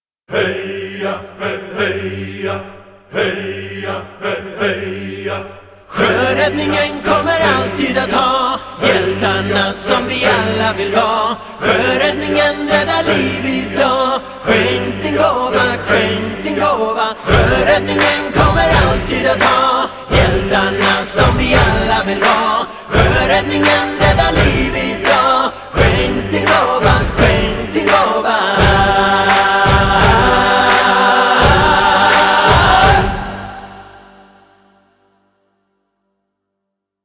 KIDS RHYME